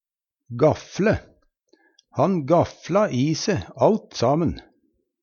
gafle - Numedalsmål (en-US)